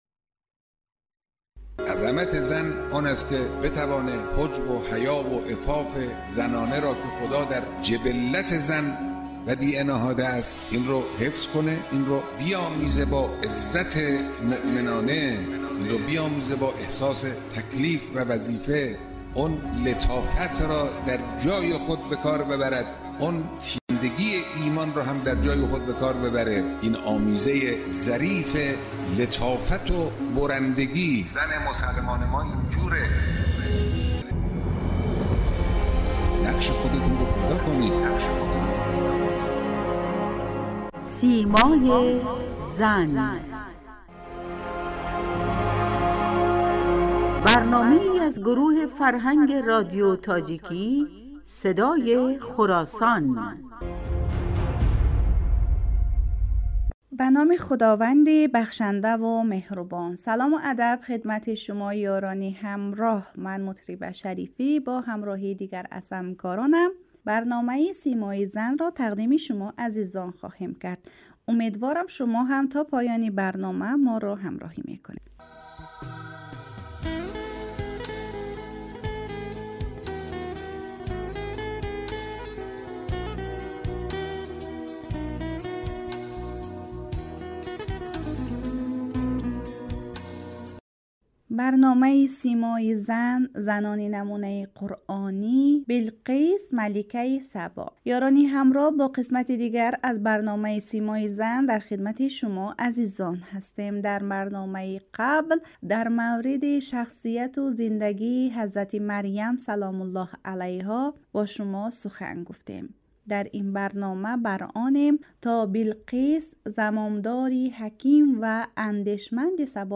"سیمای زن" با هدف تبیین جایگاه زن در اسلام در رادیو تاجیکی صدای خراسان تهیه می شود.